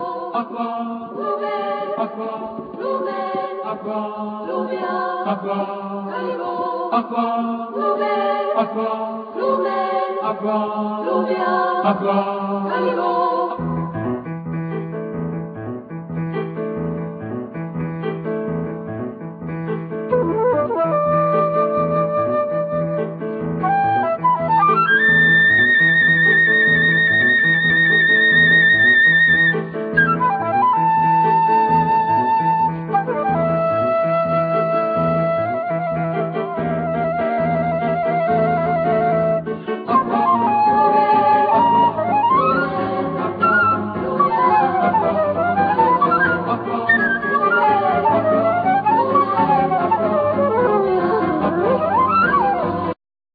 Flute,Alt flute,Alt sax, Marimba, Drums, Percussion, etc
Piano, El.piano, Cembalo
Chorus
Strings Quartet(1st Violin, 2nd Violin, Viola, Cello)